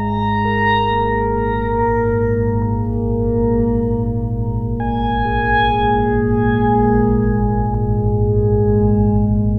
DarkFunkWaterphone_100_G#.wav